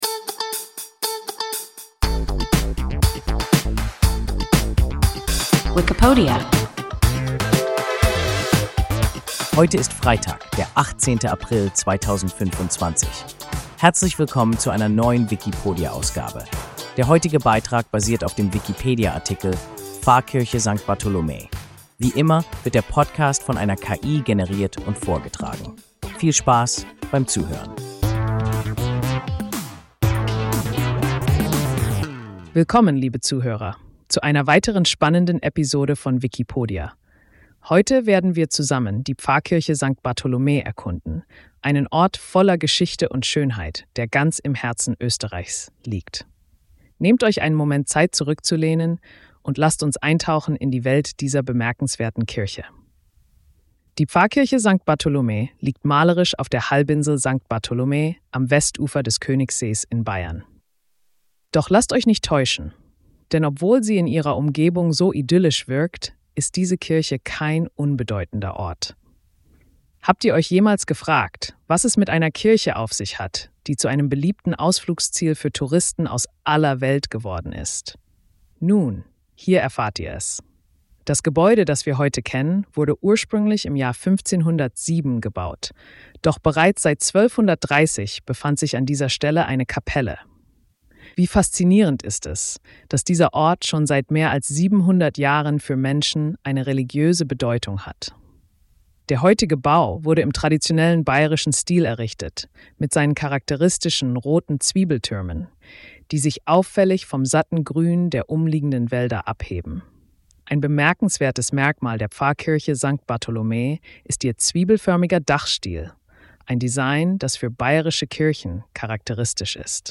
Pfarrkirche Sankt Bartholomä – WIKIPODIA – ein KI Podcast